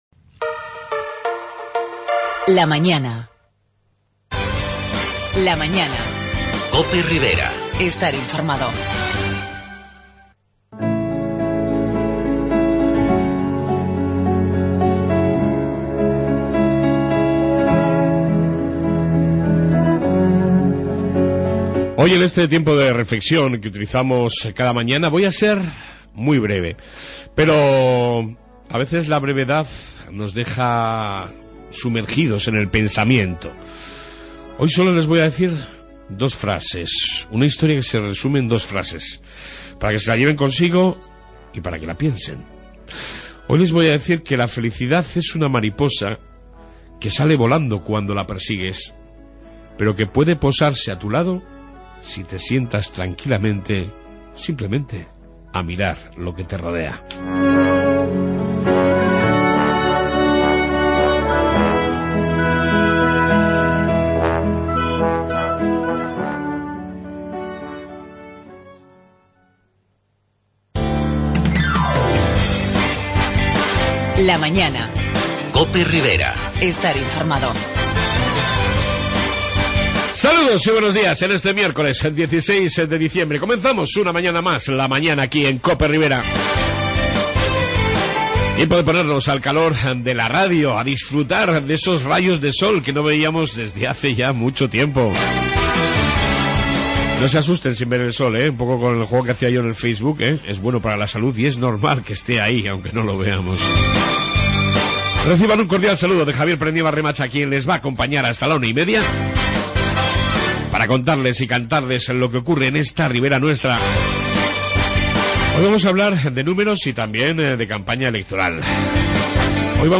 Amplia entrevista